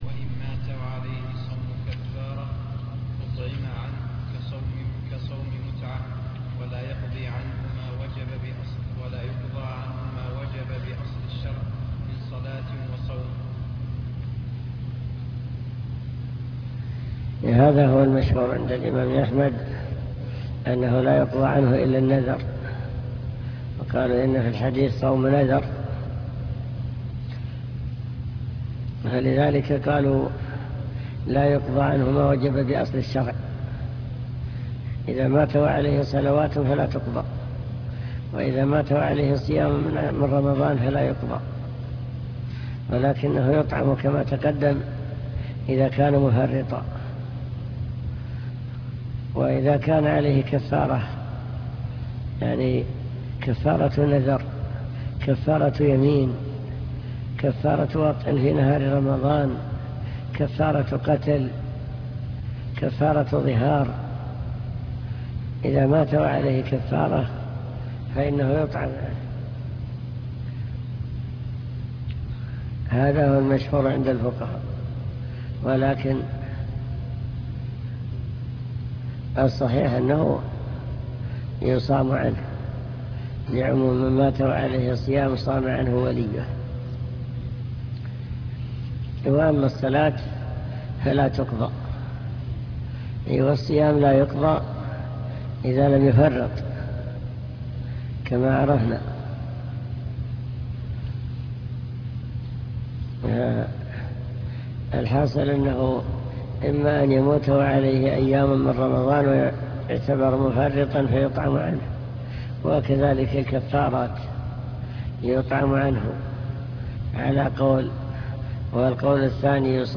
المكتبة الصوتية  تسجيلات - كتب  كتاب الروض المربع الجزء الأول قضاء الصيام